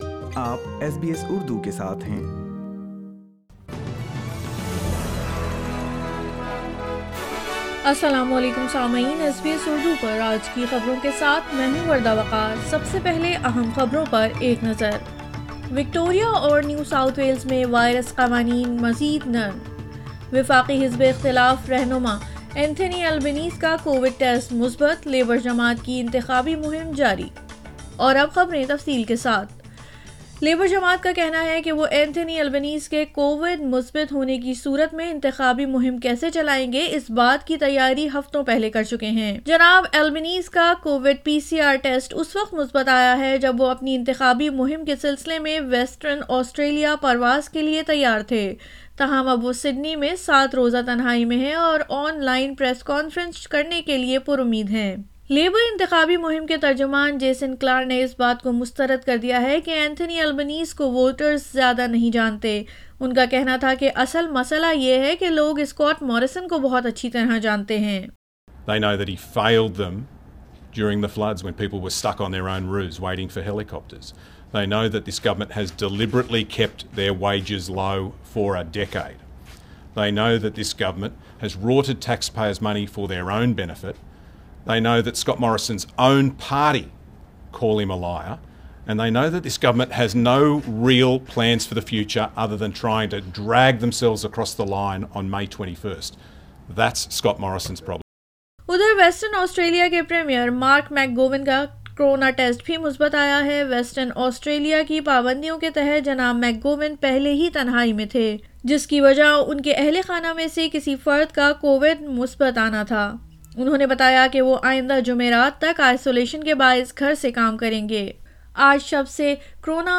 SBS Urdu News 22 April 2022